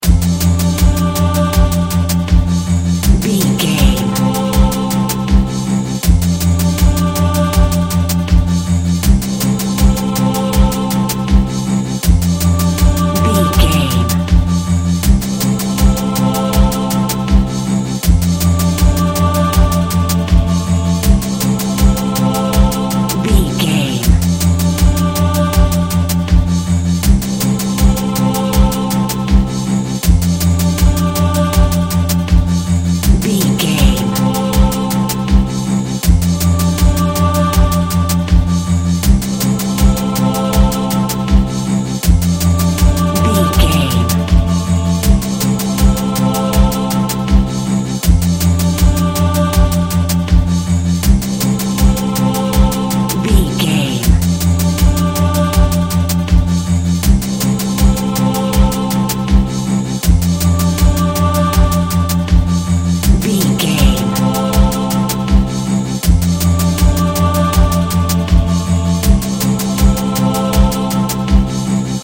A cool piece of modern chinese ethnic world music!
Ionian/Major
strings
brass
percussion
gongs
taiko drums